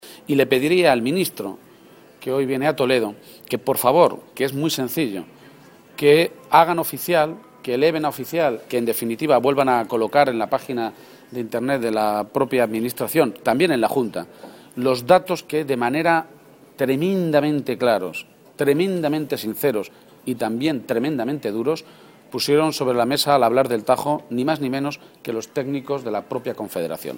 García-Page se pronunciaba de esta manera esta mañana en Toledo, a preguntas de de los medios de comunicación, y verbalizaba, además, otra exigencia Cospedal y Cañete, que está muy relacionada con esos límites en el río Tajo y su repercusión en futuro e hipotético pacto nacional sobre el agua: “Les pido públicamente que vuelvan a hacer oficial, que vuelvan a publicar en la página web del Ministerio y de la Junta las documentos y las conclusiones a las que han llegado los técnicos de la Confederación Hidrográfica del Tajo que tenemos publicados en la página web del Ayuntamiento de Toledo.
Cortes de audio de la rueda de prensa